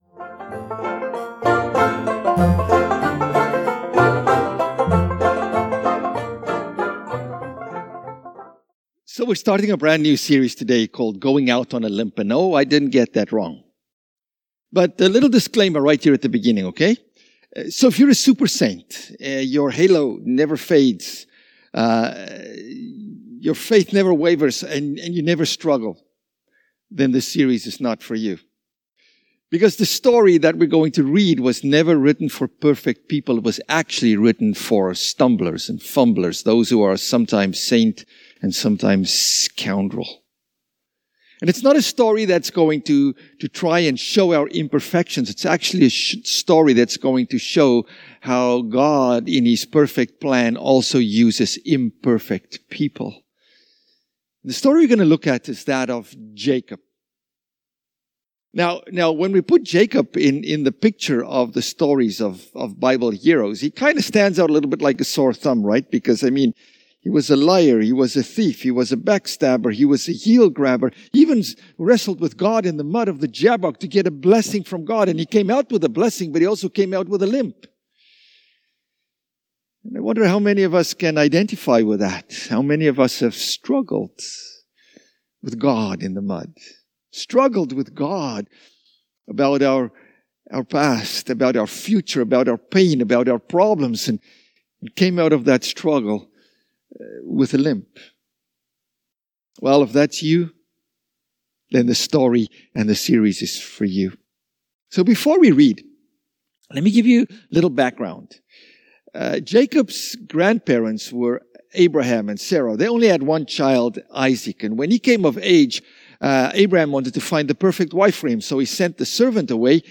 SermonApril24.mp3